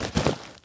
foley_sports_bag_movements_04.wav